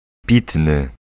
Ääntäminen
UK : IPA : /ˈpəʊ.tə.b(ə)l/ IPA : /ˈpɒt.ə.b(ə)l/